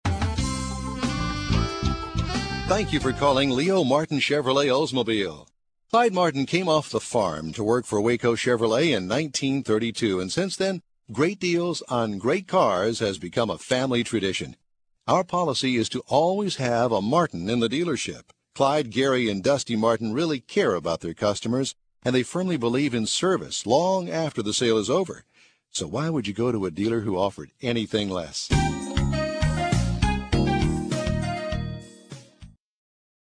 Audio Demos - Message On Hold